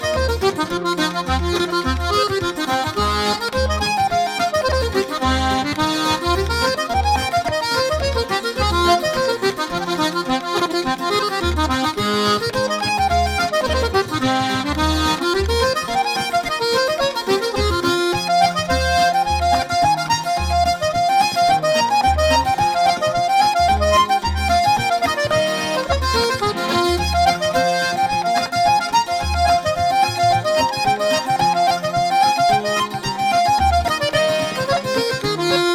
Accordion, Melodeon
Reels